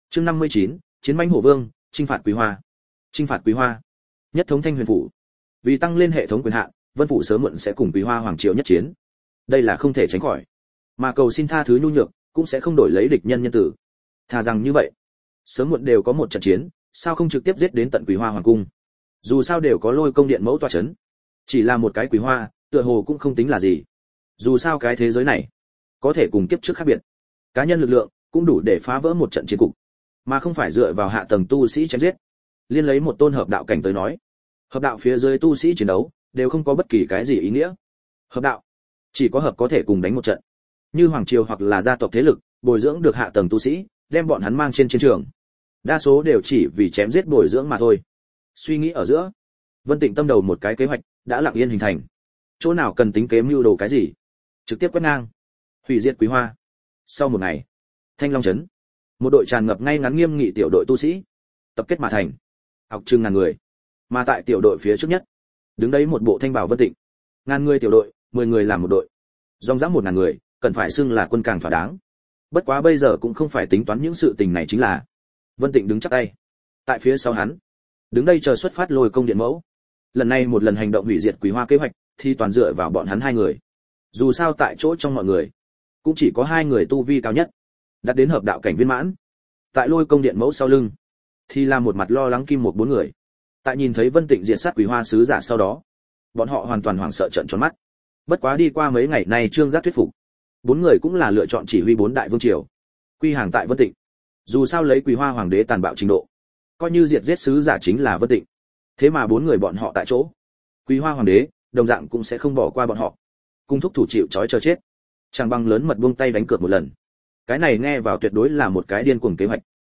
Vô Địch: Bắt Đầu Chín Khối Chín, Miểu Sát Như Lai Thần Chưởng Audio - Nghe đọc Truyện Audio Online Hay Trên TH AUDIO TRUYỆN FULL